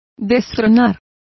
Complete with pronunciation of the translation of overthrowing.